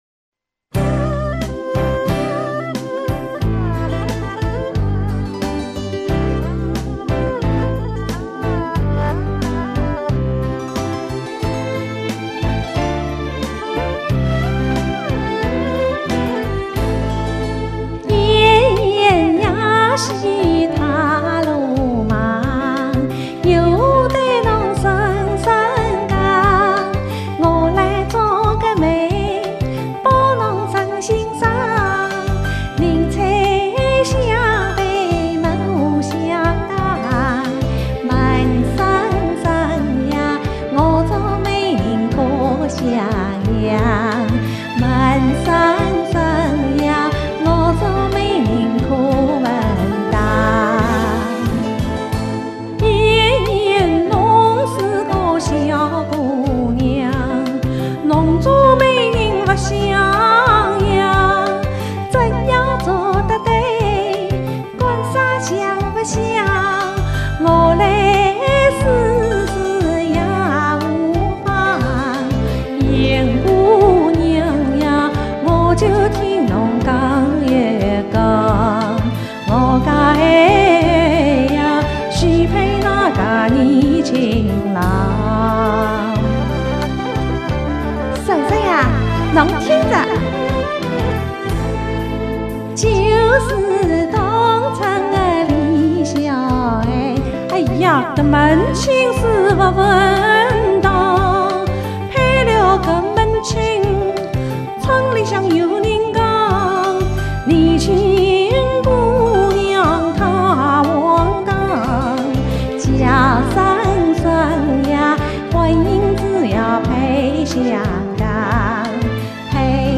【欢度春节】翻唱 --《燕燕做媒》
燕燕做媒（沪剧“罗汉钱”选段）